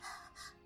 groans_03.mp3